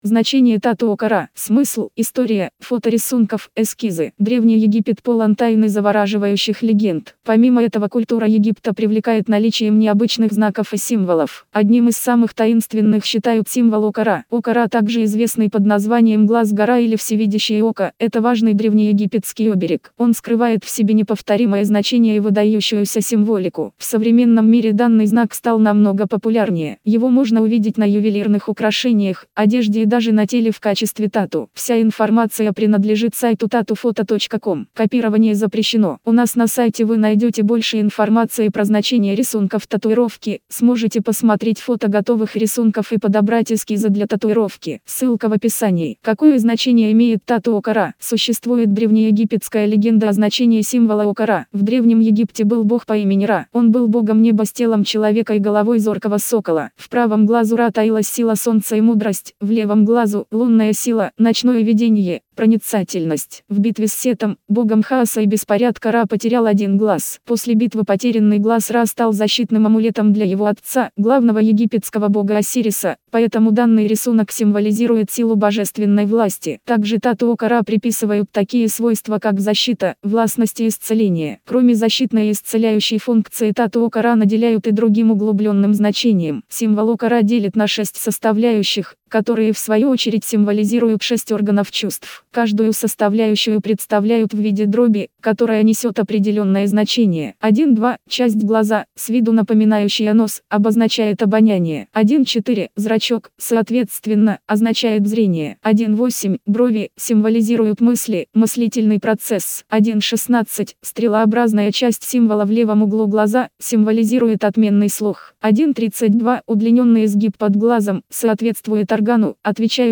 Значение-тату-Око-Ра-аудио-версия-статьи-для-сайта-tatufoto.com_.mp3